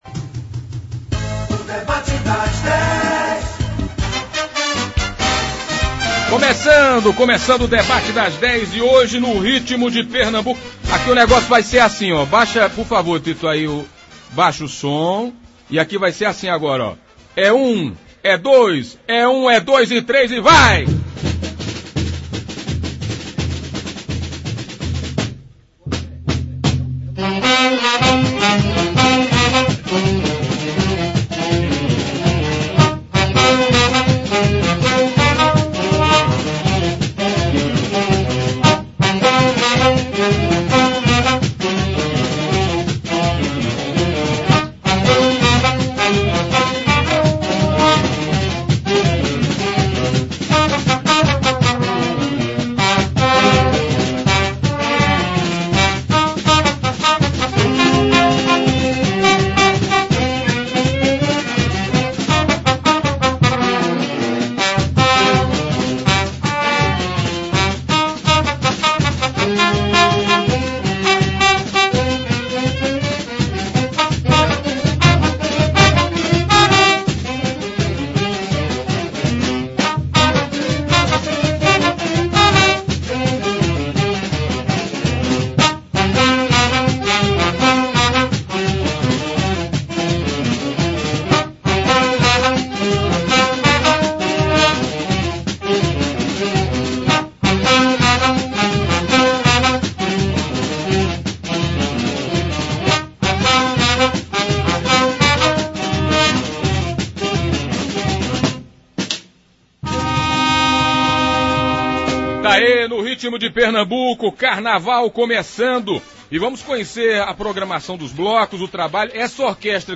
Hoje o Debate das Dez da Rádio Pajeú conseguiu reunir representantes da maioria dos blocos que farão o carnaval de Afogados da Ingazeira. Com eles músicos da Orquestra Jogando Brasa, da cidade de Carnaíba.